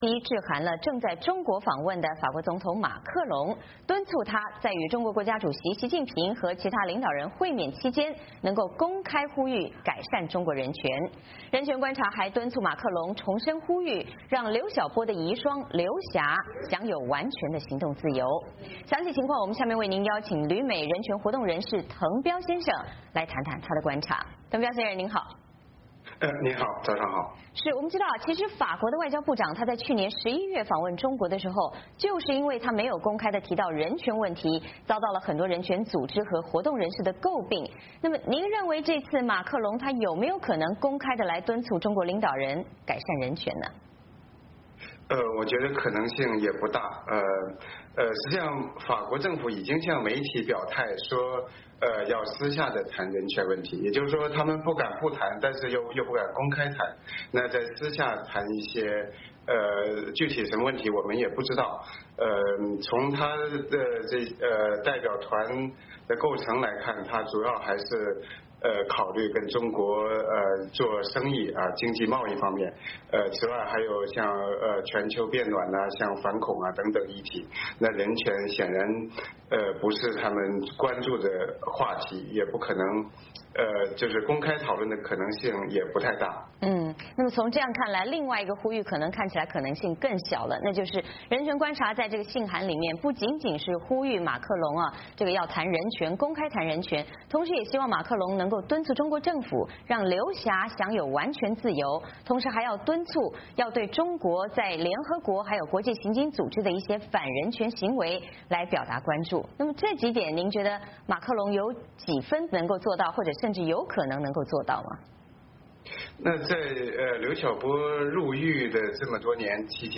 马克龙访华期间是否有可能提及人权问题呢？详细情况，旅美人权活动人士滕彪先生为您谈谈他的观察。